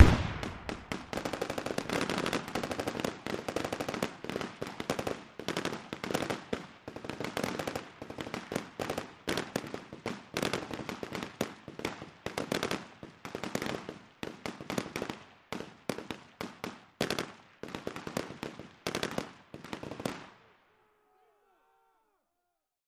Battle simulation with rapid fire weapons. Weapons, Gunfire Bombs, War Battle, Military